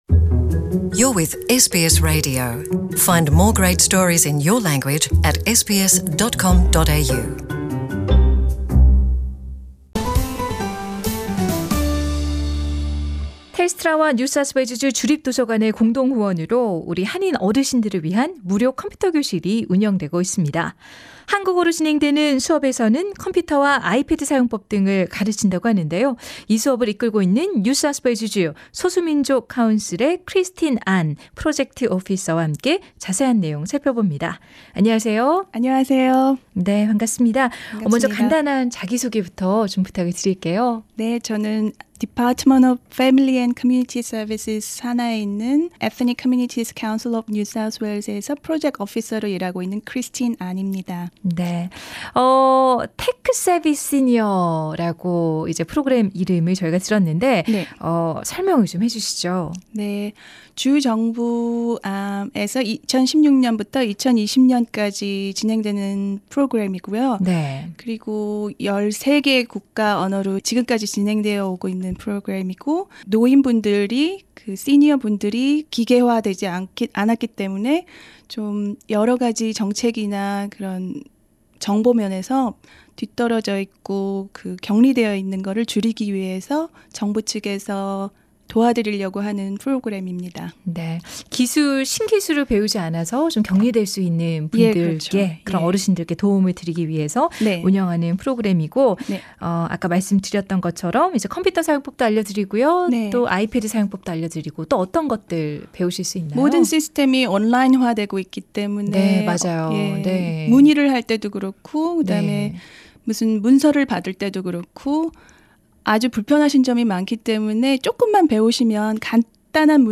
The Full interview is available on the podcast above.